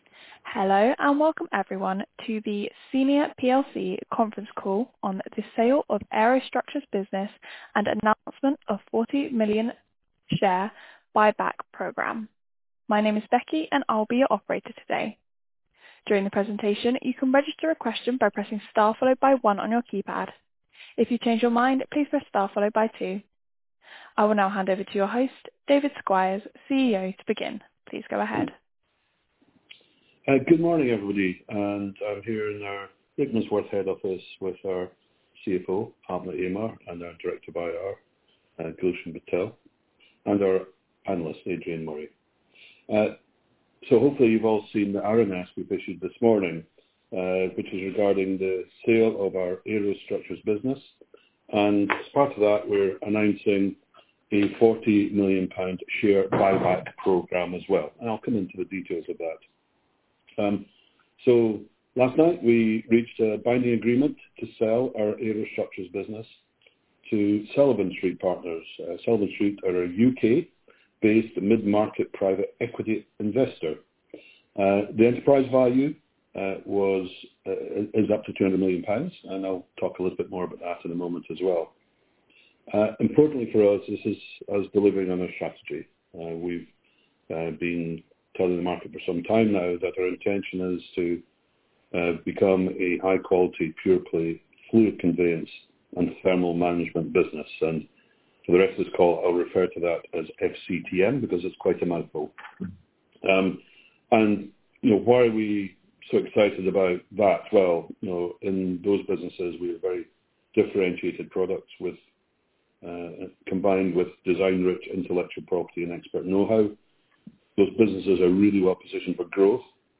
Please find the recording to the conference call held Friday 18 July 2025 at 8.30am BST here